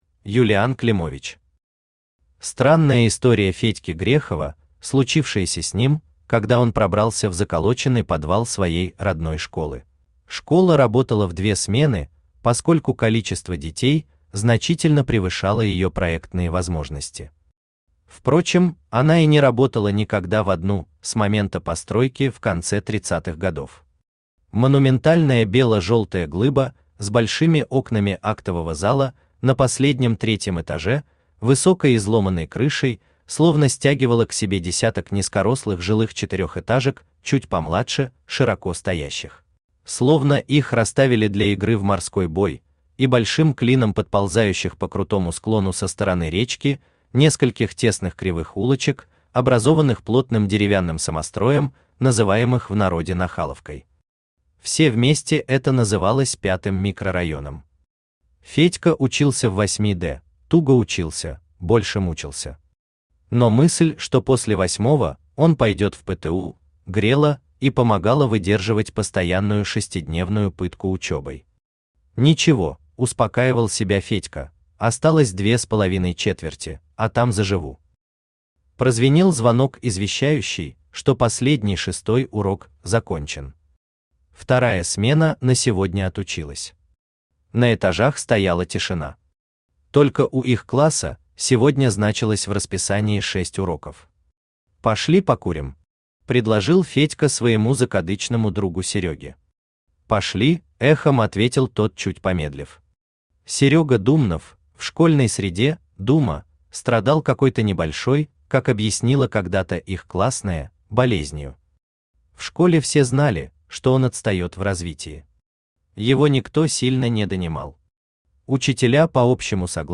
Аудиокнига Странная история Федьки Грехова, случившаяся с ним, когда он пробрался в заколоченный подвал своей родной школы | Библиотека аудиокниг
Aудиокнига Странная история Федьки Грехова, случившаяся с ним, когда он пробрался в заколоченный подвал своей родной школы Автор Юлиан Климович Читает аудиокнигу Авточтец ЛитРес.